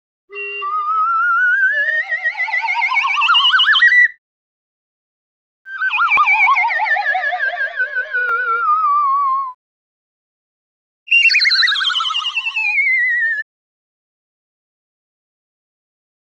slide_whistle_3x.wav